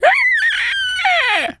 pig_scream_04.wav